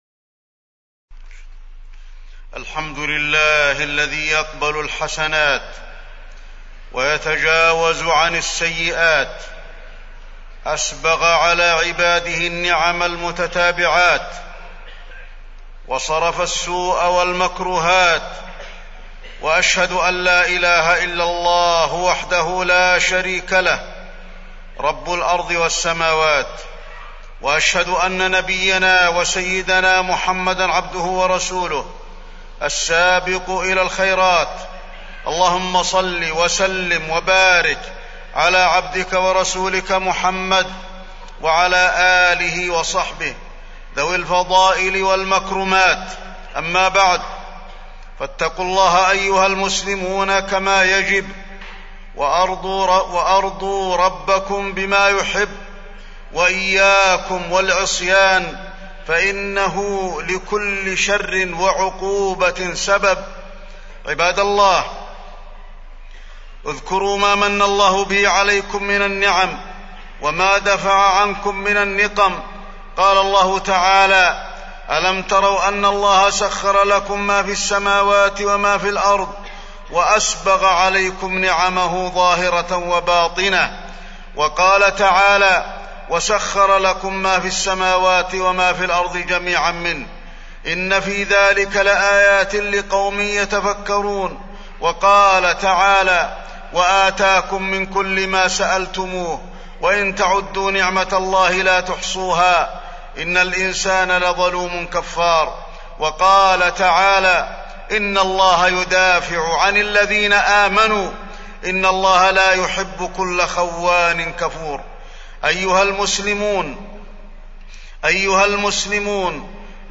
تاريخ النشر ١٣ ذو الحجة ١٤٢٦ هـ المكان: المسجد النبوي الشيخ: فضيلة الشيخ د. علي بن عبدالرحمن الحذيفي فضيلة الشيخ د. علي بن عبدالرحمن الحذيفي الأعمال بعد الحج